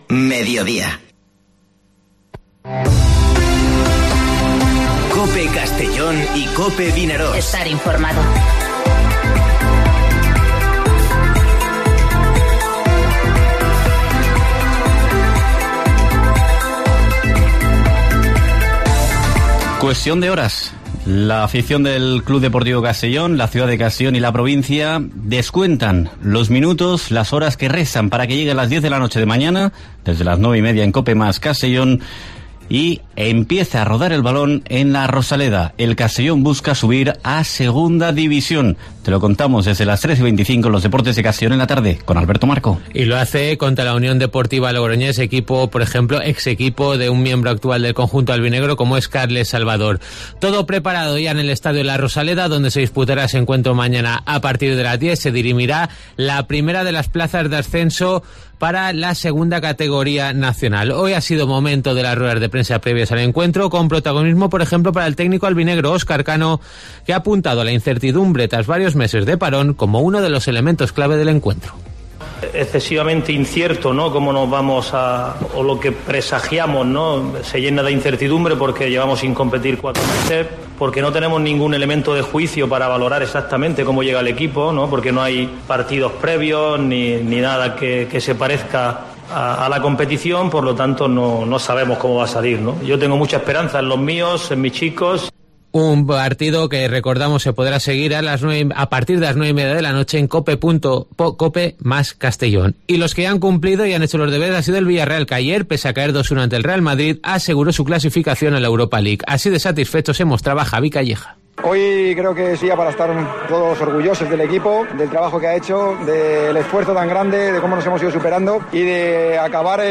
Informativo Mediodía COPE en la provincia de Castellón (17/07/2020)